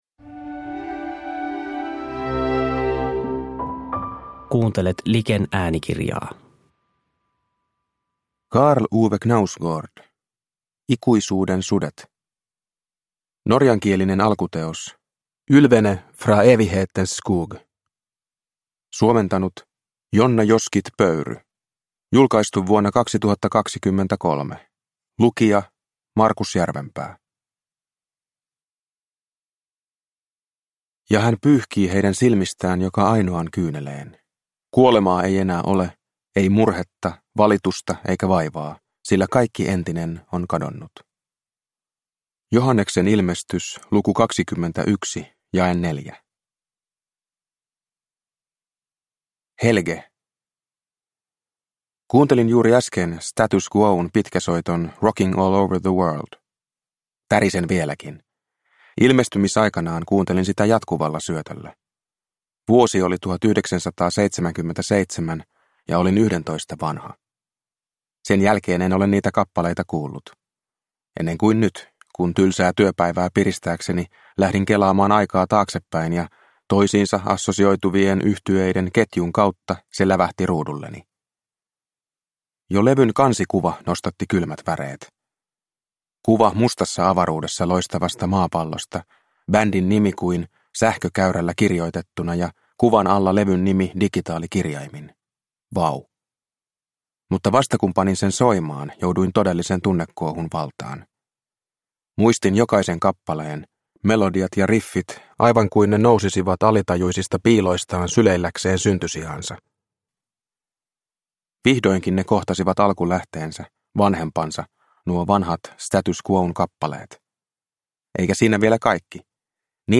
Ikuisuuden sudet – Ljudbok